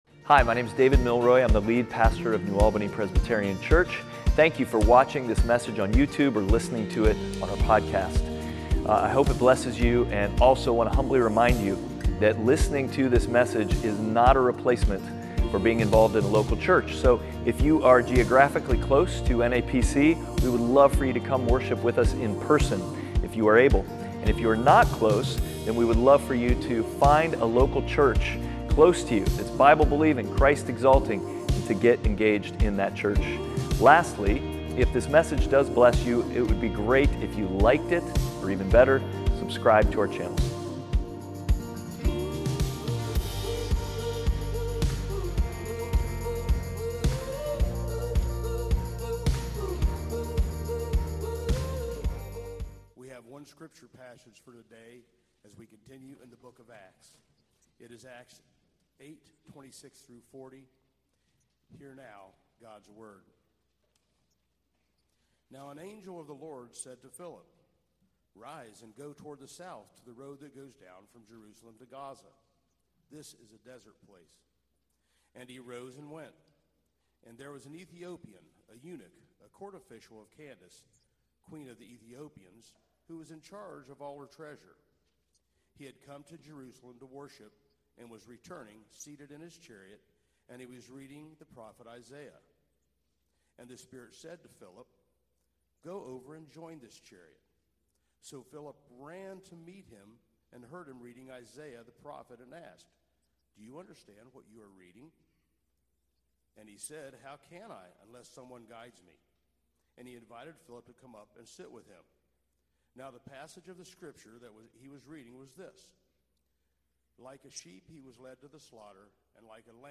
Acts 8:26-40 Service Type: Sunday Worship « Outward